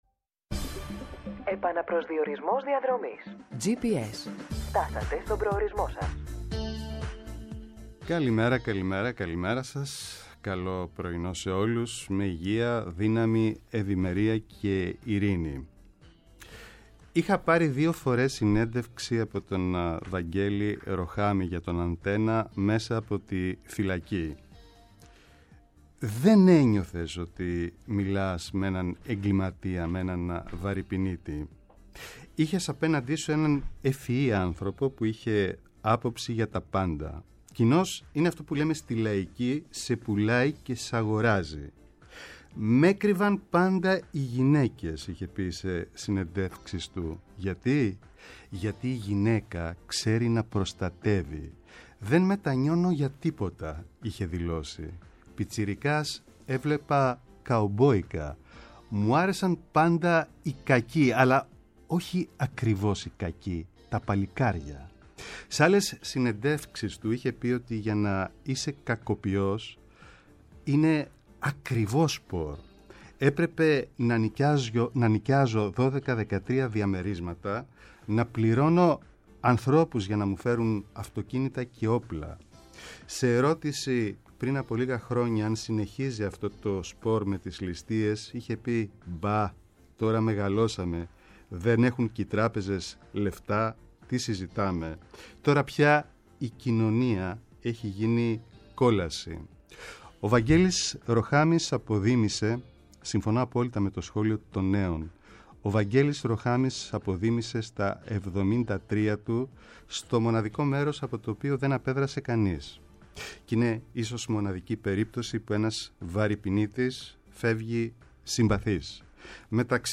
αποκαλυπτικές συνεντεύξεις και πλούσιο ρεπορτάζ